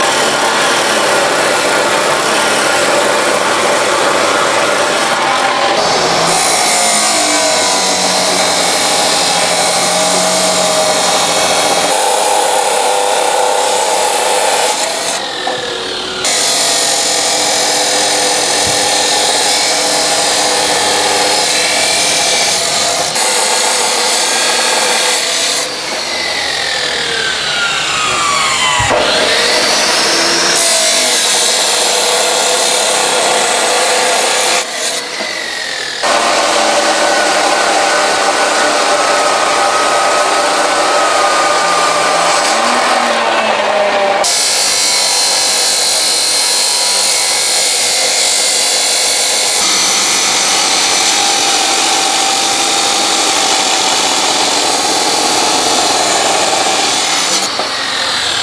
描述：一个物理钟，电动操作，在一个典型的工厂里开始工作时响起。声音是用Rode NT4立体声麦克风和Edirol R44录音机录制的
声道立体声
工厂开始工作的电铃声
描述：工厂开始工作的电铃声，声音是用Rode NT4立体声麦克风和Edirol R44录音机录制的。